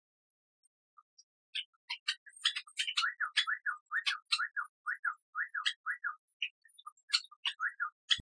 ↓ ※音が小さいです ↓
ヘイスケの詩吟 ・・・飼い主をまねたヘイスケ努力のさえずり
擬音化「（ヒョ-、）ヒョヒョヒョン・ヒョヒョヒョン・ヒョヒョヒョン（繰り返し）